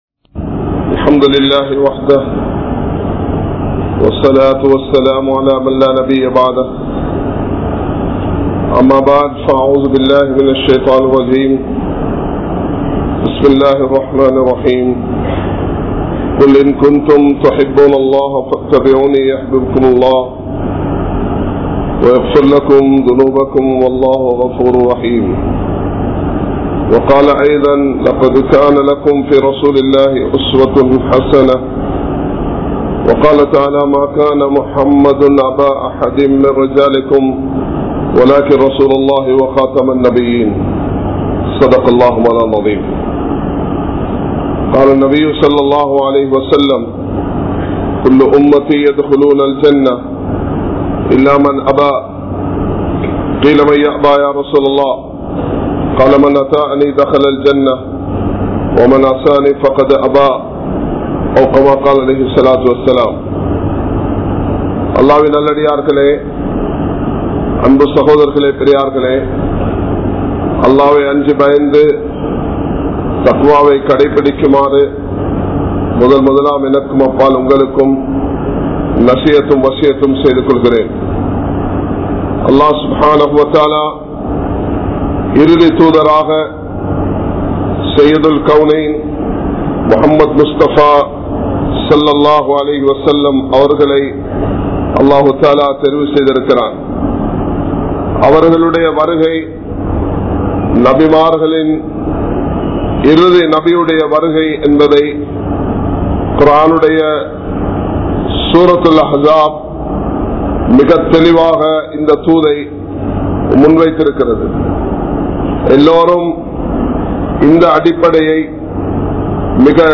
Nabi(SAW)Avarhalin Sunnah (நபி(ஸல்) அவர்களின் சுன்னா) | Audio Bayans | All Ceylon Muslim Youth Community | Addalaichenai